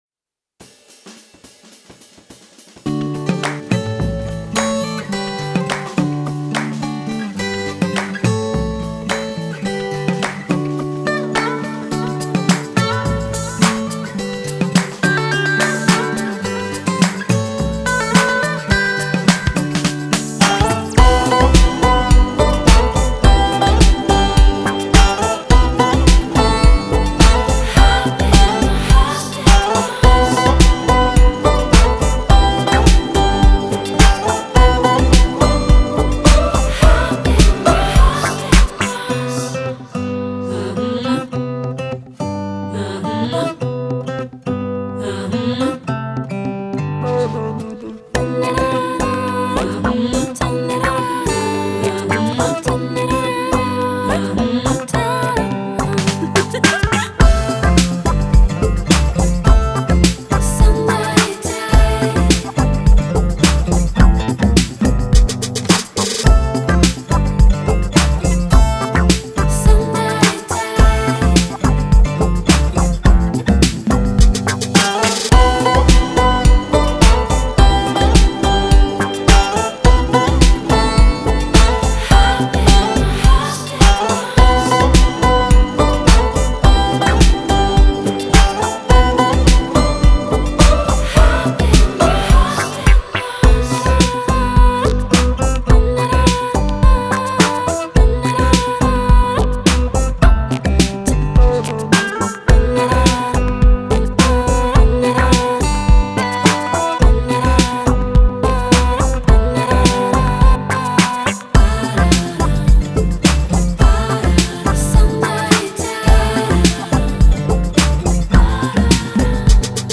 Запись, сведение, инструменты — студия звука